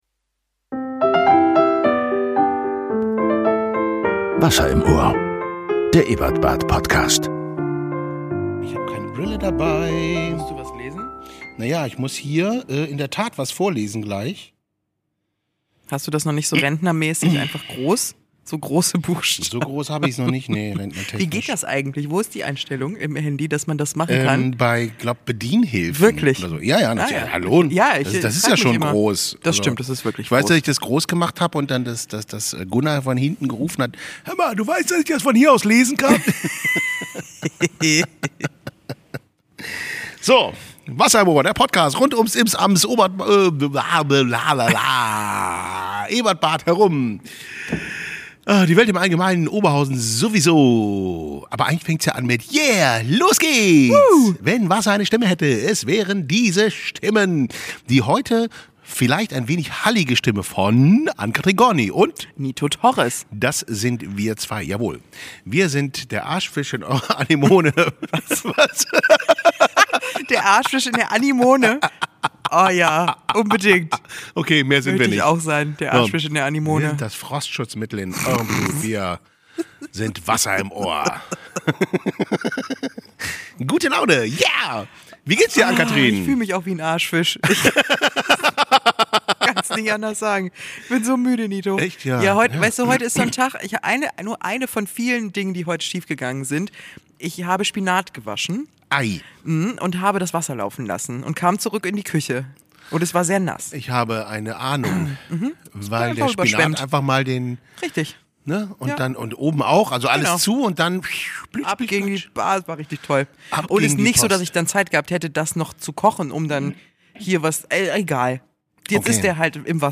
Im Interview: Ingo Oschmann. Frösche, Frostschutz, Furzgrundeln, Igel und Riesenrattenkängurus.